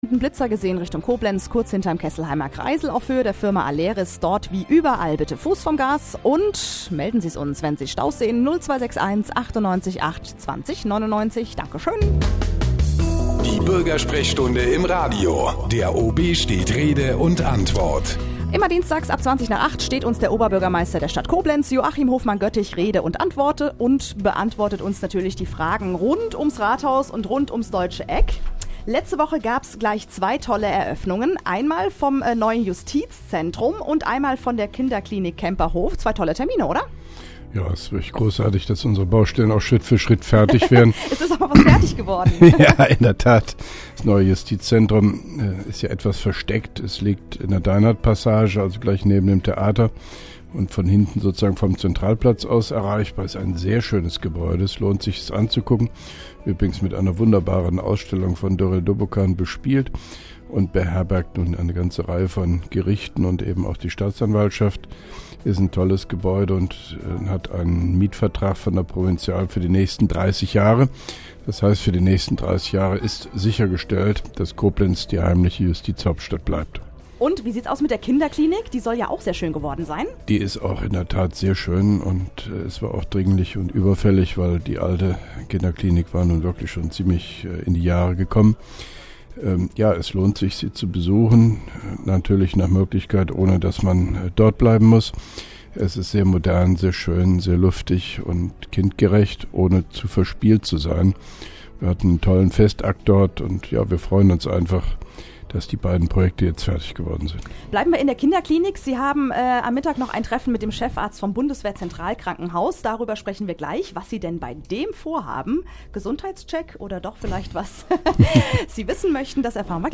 (2) Koblenzer Radio-Bürgersprechstunde mit OB Hofmann-Göttig 08.02.2011